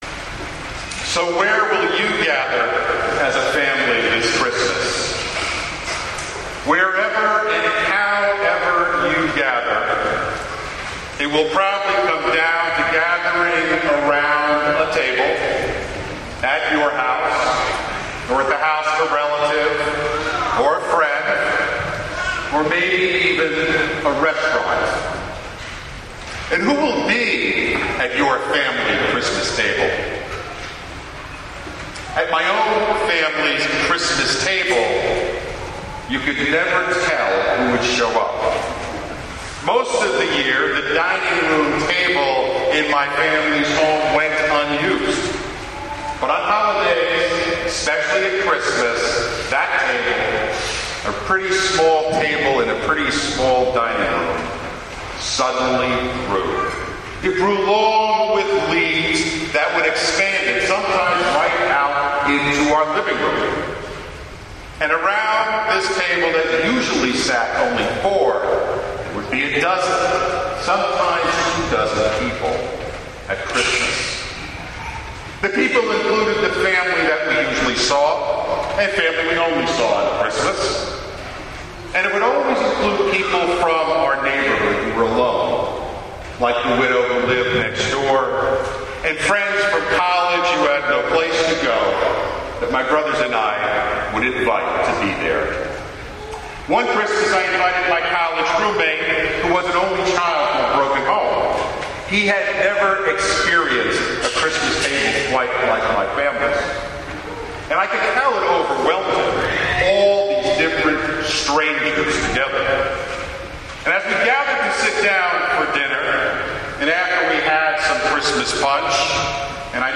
Christmas Eve